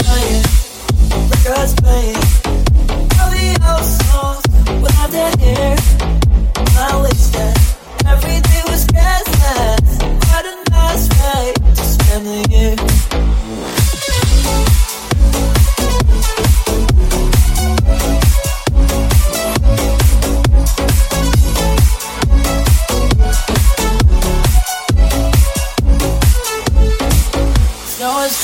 Genere: edm,deep,bounce,house,slap,cover,remix hit